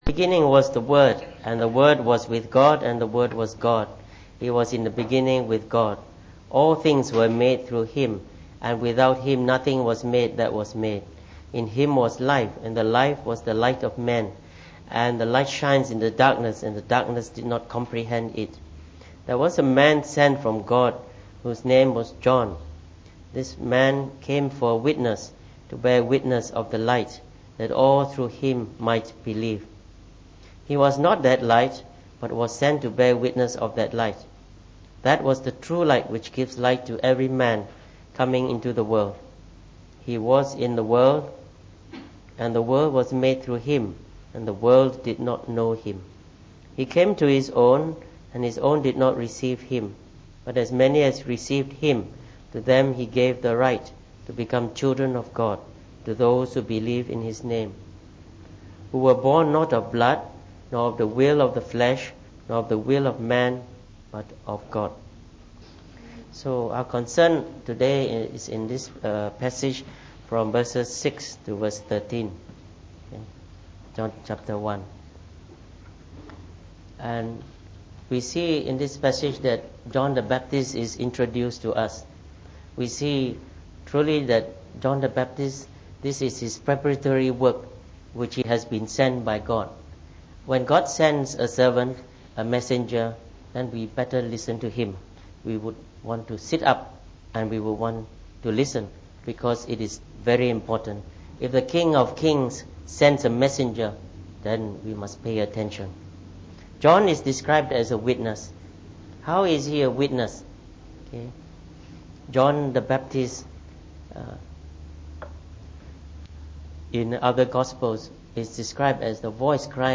Preached on the 8th of February 2015.